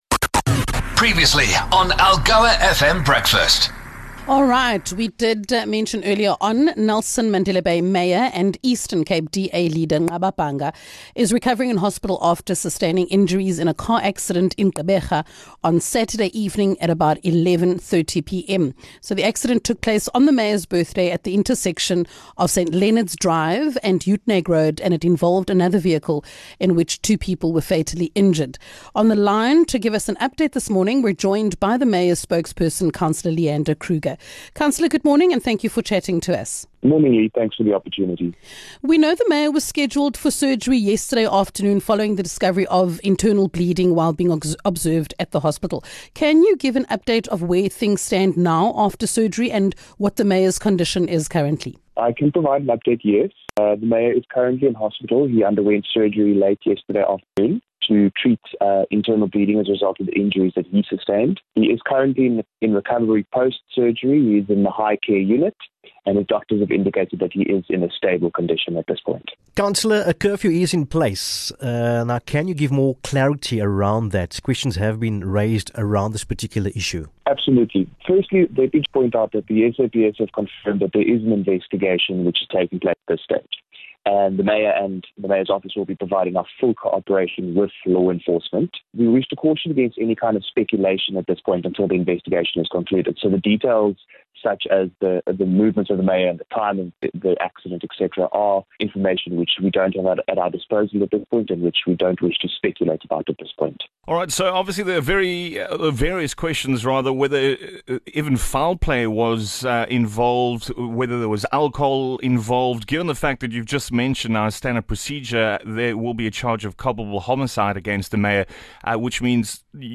DA councillor, Leander Kruger, joined the Breakfast Team to give an update on Mayor Nqaba Bhanga's condition following Saturday night's tragic accident. He also addressed various allegations doing the rounds.